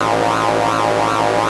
LabLaserLoop.wav